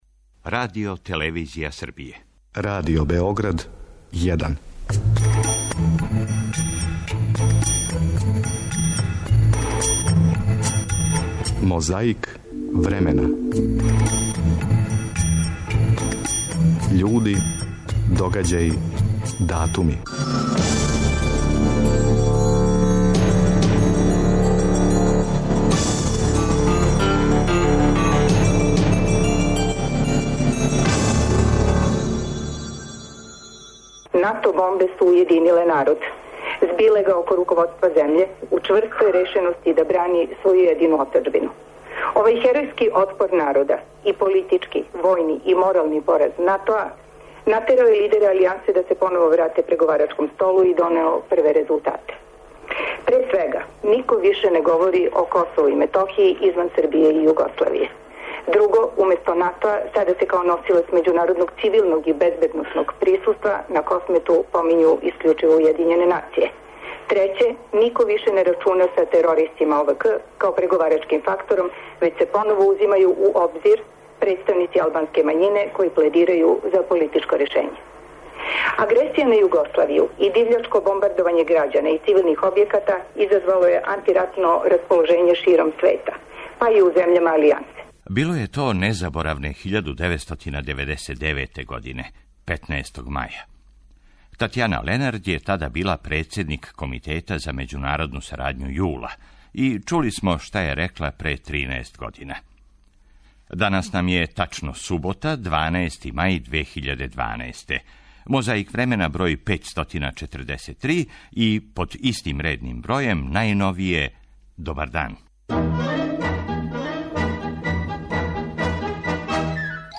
Маја 2002. године, у оквиру акције Србија на добром путу, премијер Зоран Ђинђић говорио је у Шапцу...
Подсећа на прошлост (културну, историјску, политичку, спортску и сваку другу) уз помоћ материјала из Тонског архива, Документације и библиотеке Радио Београда.